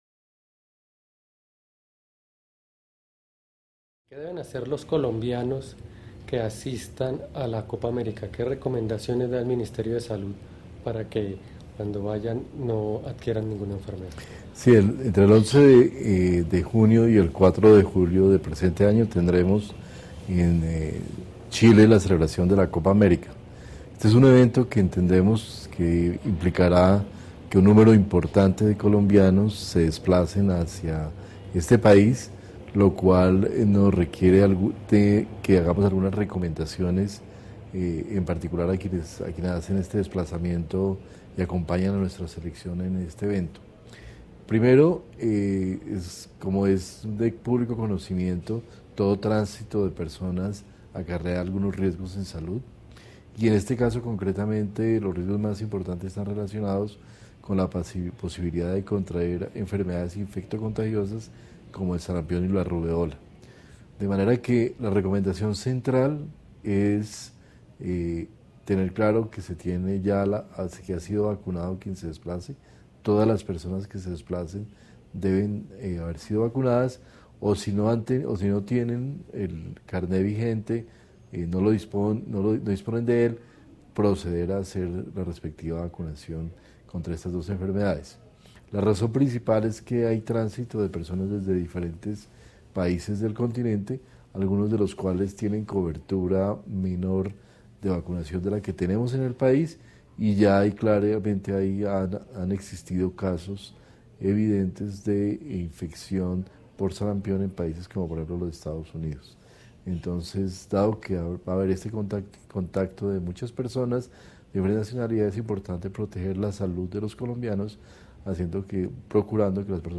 Viceministro_Copa_America_Chile.mp3